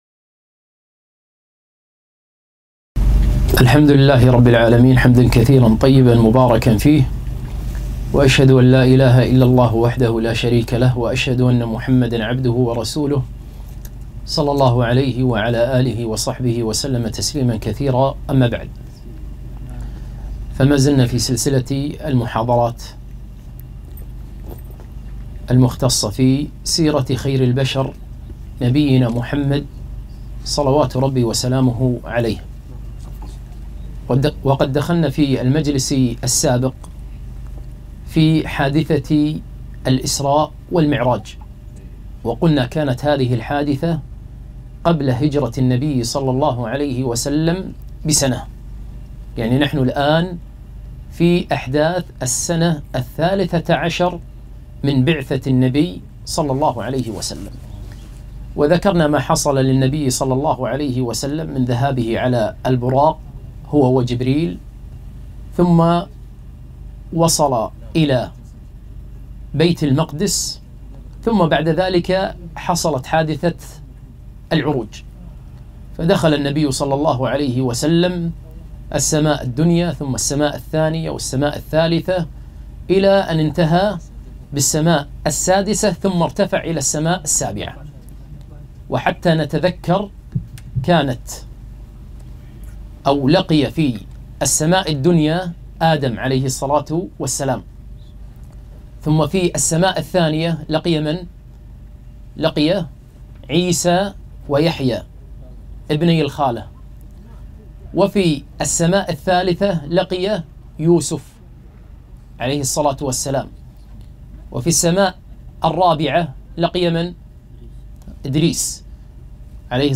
31- تفاصيل وأسرار رحلة الإسراء والمعراج (المحاضرة الثانية)